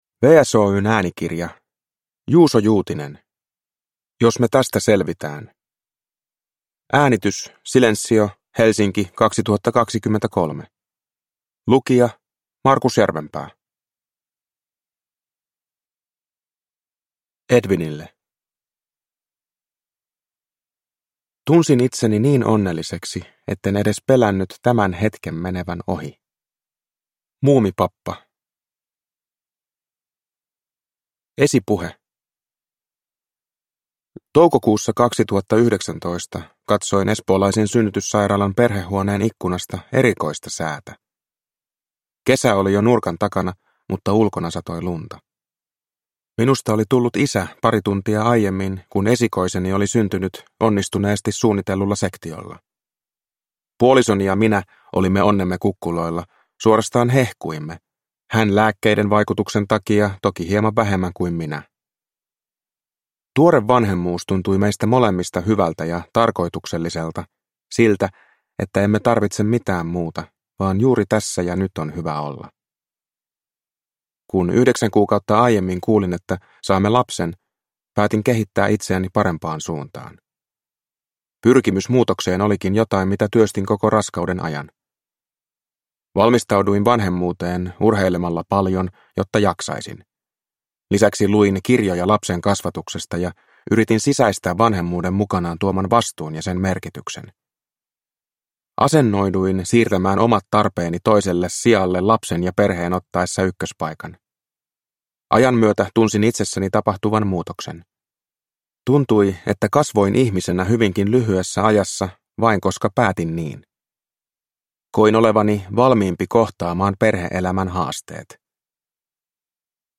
Jos me tästä selvitään – Ljudbok